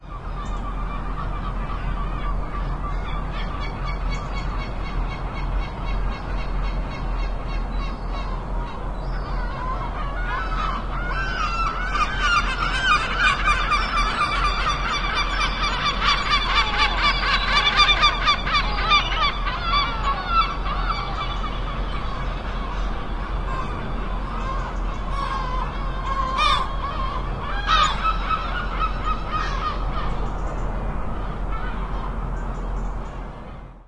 描述：各种鸟，主要是海鸥。用索尼M10在我的公寓窗户上录制。
Tag: 城市 海鸥 乌鸦